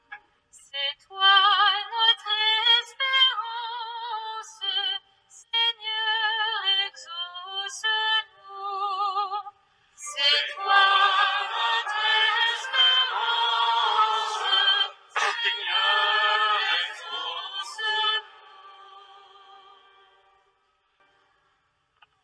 Messe solennelle dans la pro-cathédrale Saint-Etienne de Nevers.
Animation de l’invocation